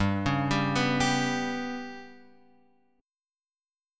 GMb5 chord {3 4 5 4 x 3} chord